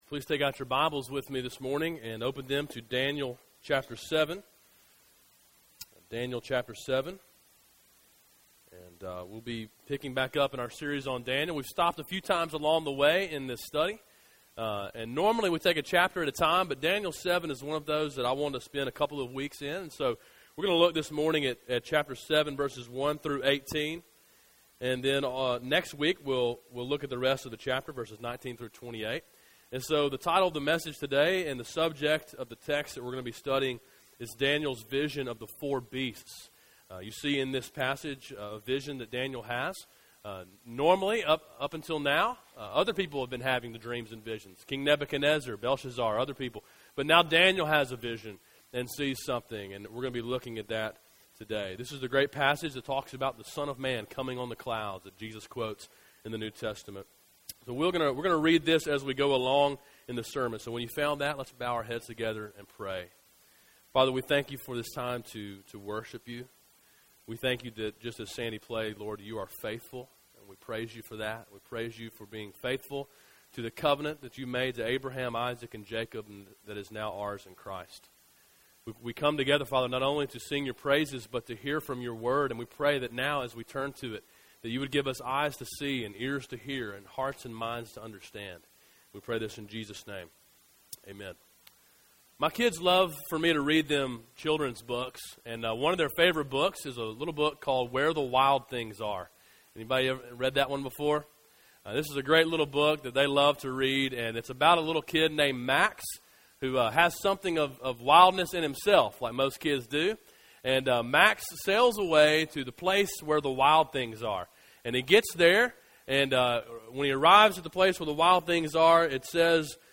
A sermon in a series on the book of Daniel.